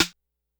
Perc_129.wav